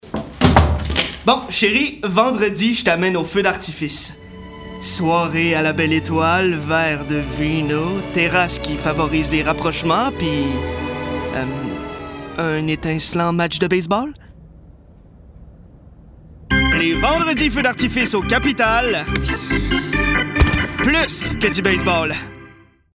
Publicité 1